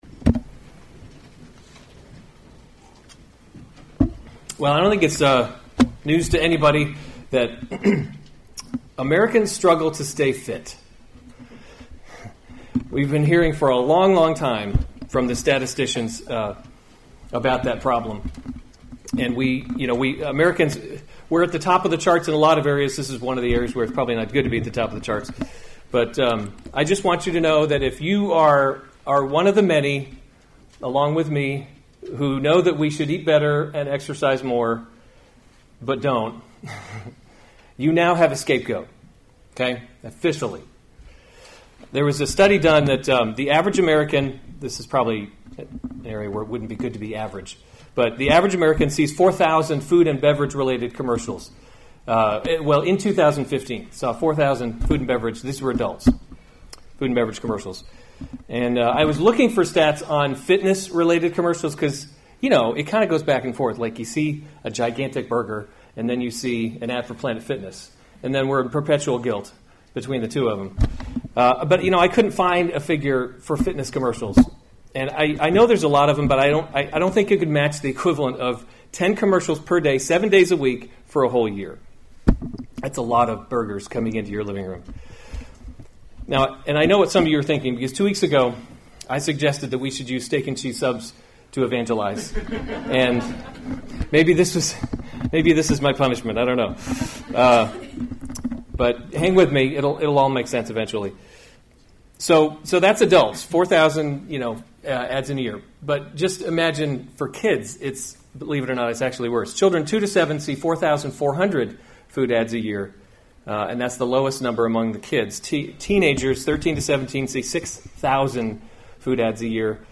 April 22, 2017 1 Timothy – Leading by Example series Weekly Sunday Service Save/Download this sermon 1 Timothy 4:6-10 Other sermons from 1 Timothy A Good Servant of Christ Jesus […]